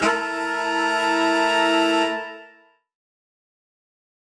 UC_HornSwellAlt_Bmin9.wav